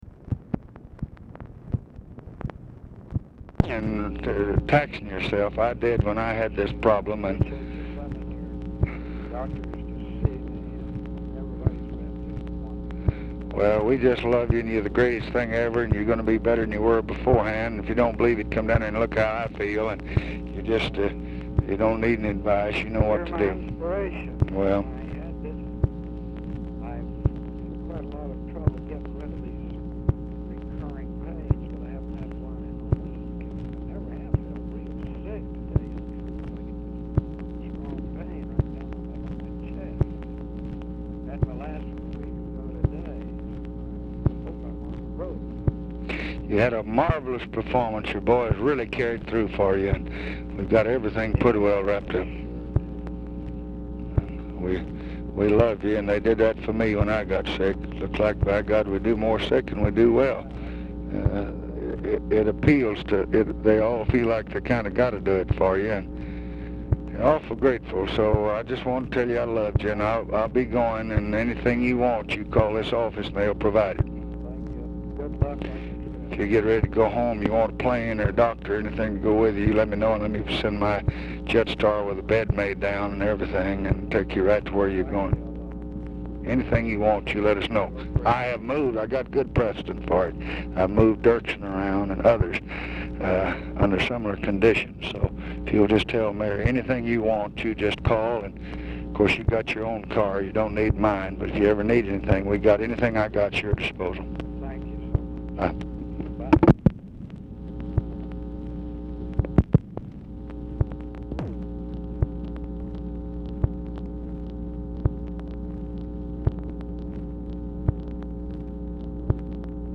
Telephone conversation # 10947, sound recording, LBJ and CARL ALBERT, 10/15/1966, 11:56AM
RECORDING STARTS AFTER CONVERSATION HAS BEGUN; ALBERT IS DIFFICULT TO HEAR
Format Dictation belt